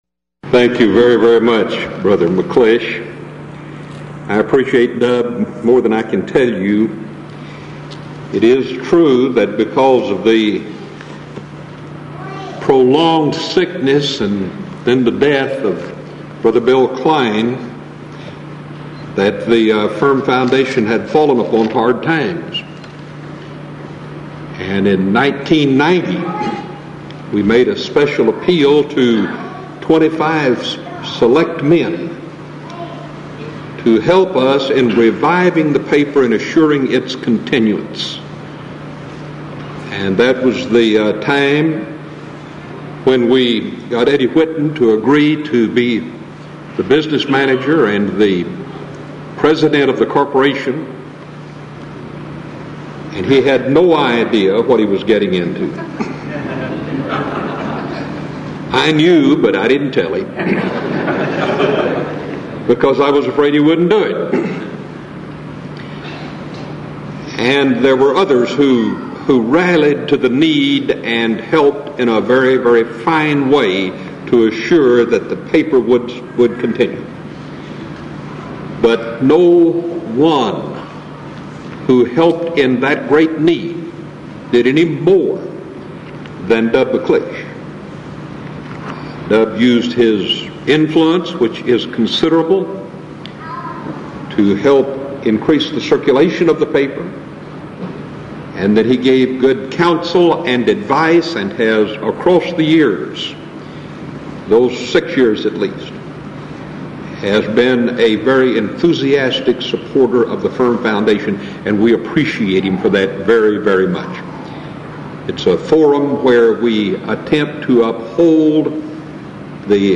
Title: DISCUSSION FORUM: Does The Grace Of God Guarantee Our Final Salvation?
Event: 1996 Denton Lectures